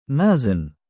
母音記号あり：مَازِن [ māzin ] [ マーズィン ]